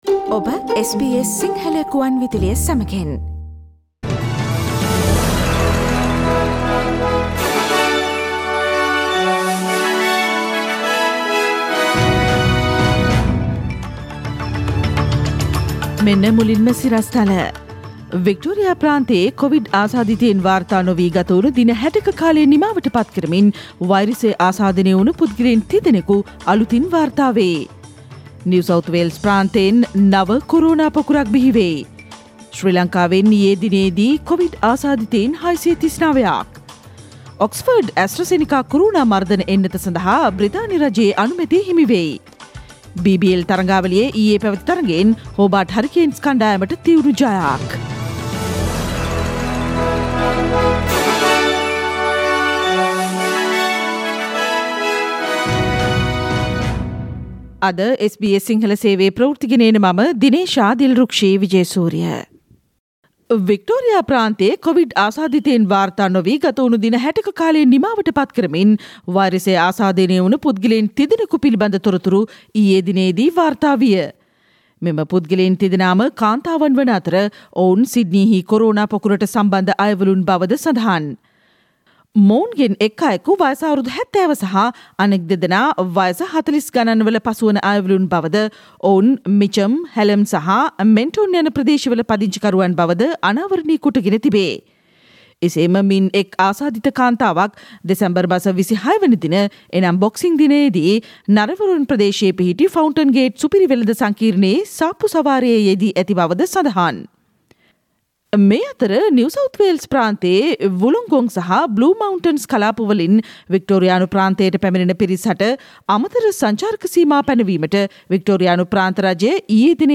Today’s news bulletin of SBS Sinhala radio – Thursday 31 December 2020.